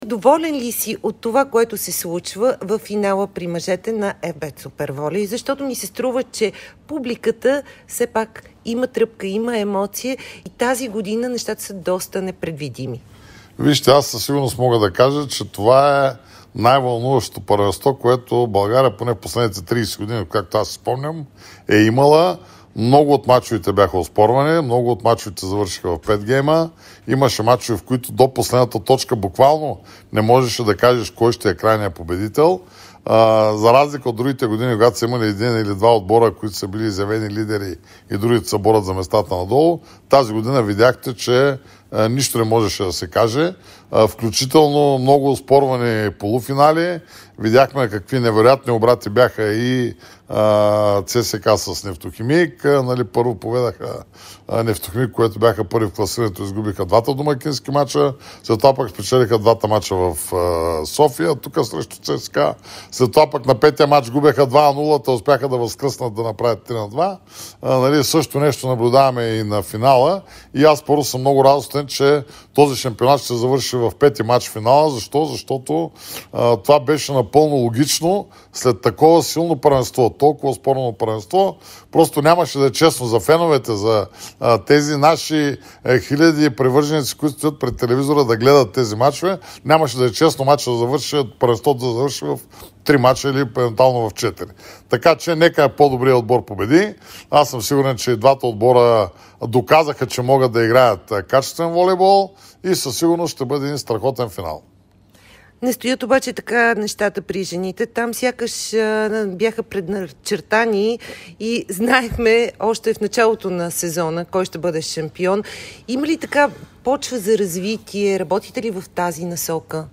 Президентът на БФ Волейбол Любо Ганев даде специално интервю пред Дарик радио и dsport преди предстоящия решаващ пети мач от финалната серия на Националната волейболна лига при мъжете.